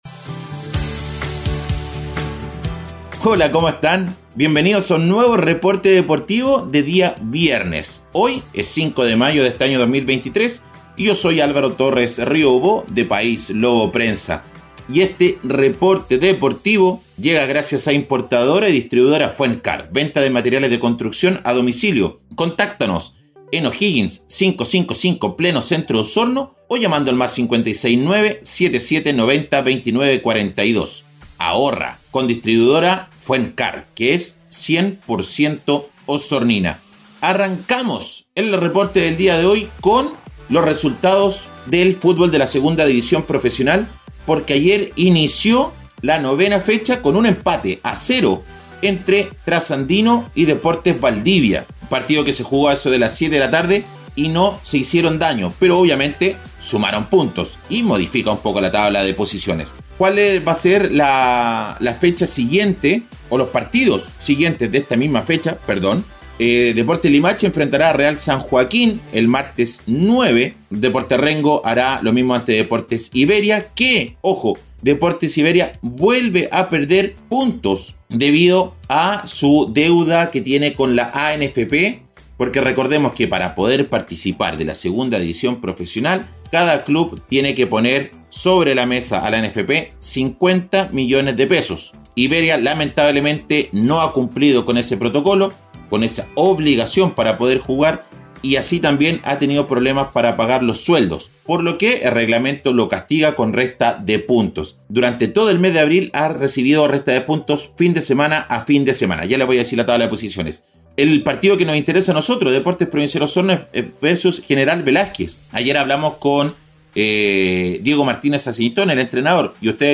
Reporte Deportivo 🎙 Podcast 05 de mayo de 2023